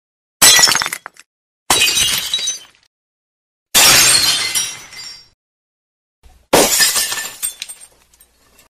Звуки стакана
Звук разбитых стеклянных стаканов